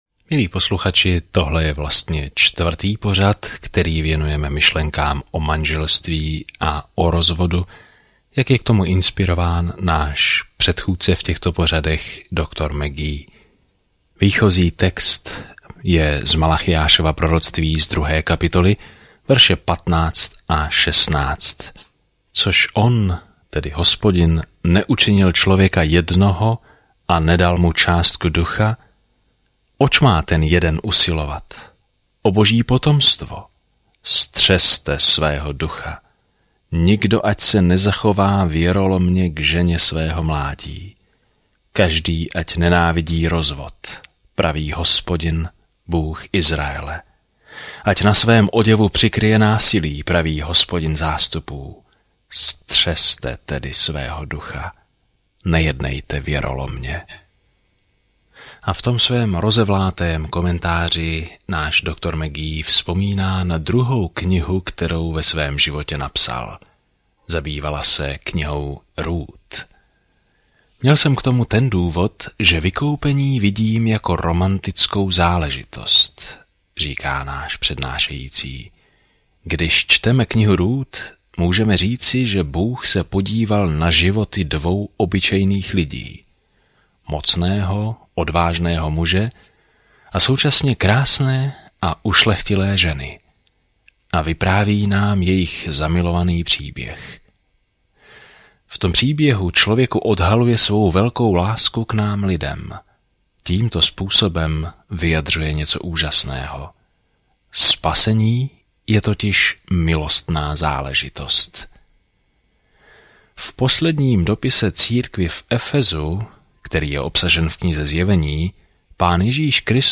Písmo Malachiáš 2:16 Den 8 Začít tento plán Den 10 O tomto plánu Malachiáš připomíná odpojenému Izraeli, že má poselství od Boha, než vydrží dlouhé ticho – které skončí, když na scénu vstoupí Ježíš Kristus. Denně procházejte Malachiášem, když posloucháte audiostudii a čtete vybrané verše z Božího slova.